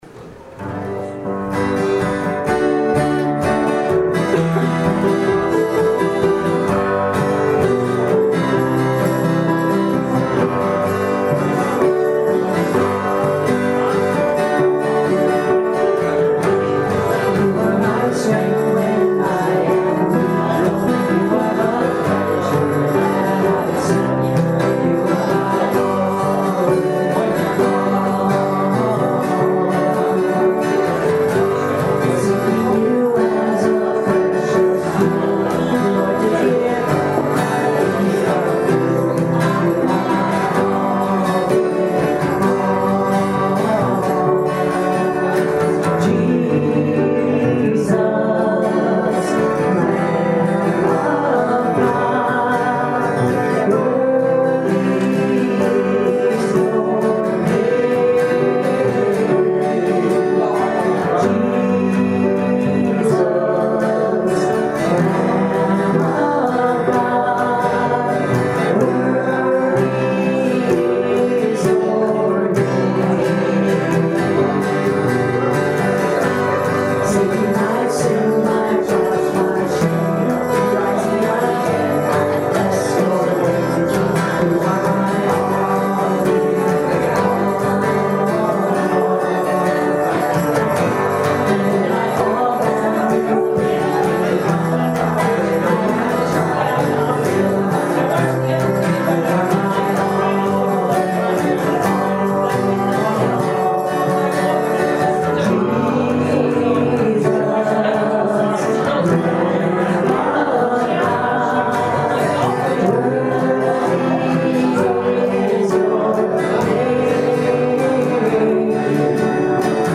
September 11th, 2016 Service Podcast
Welcome to the September 11th, 2016 Service Podcast.